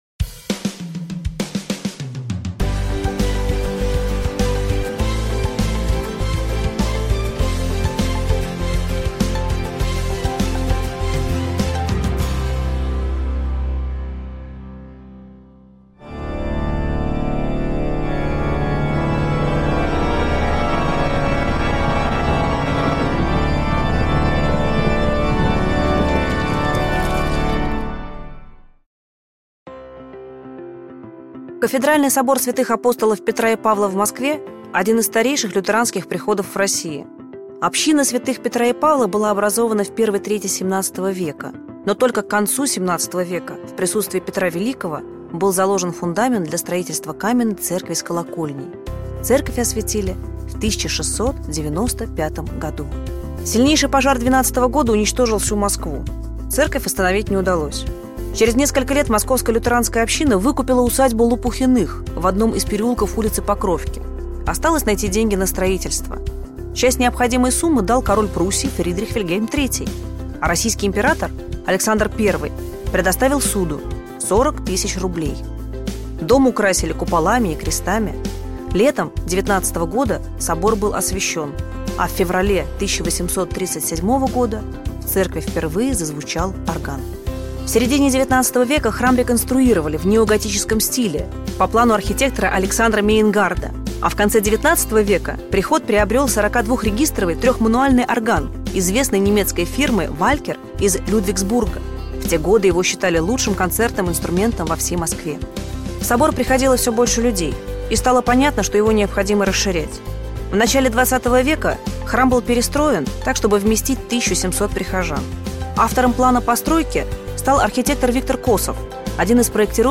Аудиокнига Праведные пчелы и правильные стерхи | Библиотека аудиокниг